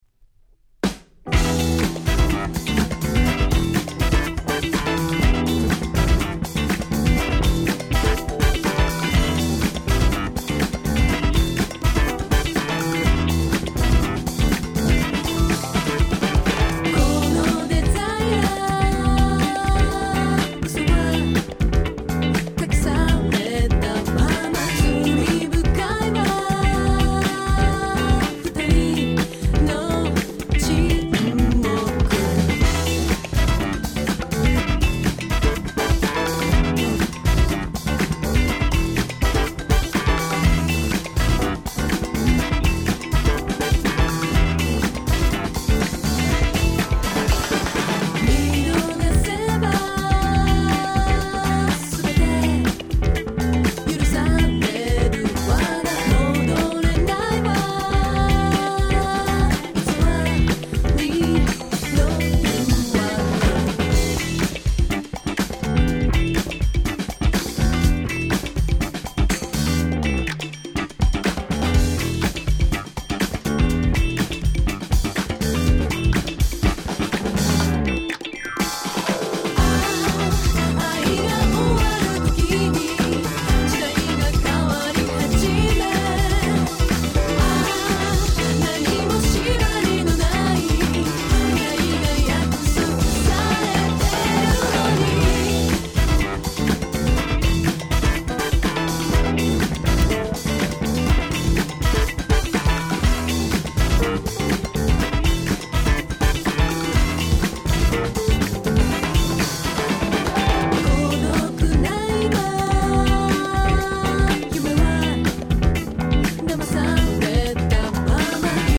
【Media】Vinyl 7'' Single
19' Nice Japanese R&B !